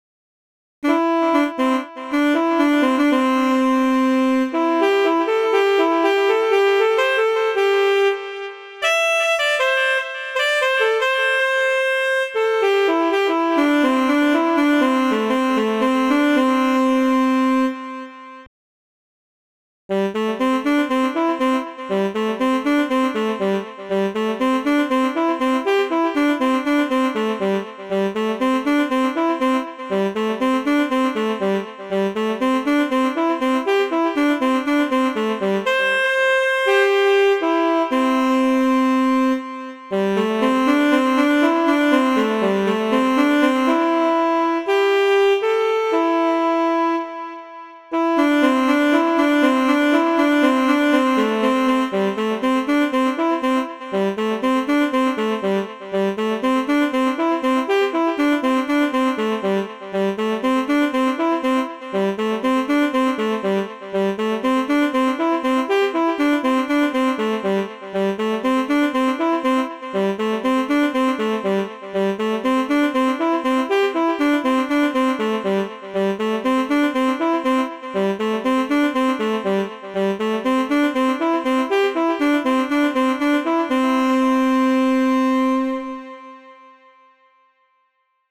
sax sola_Current.wav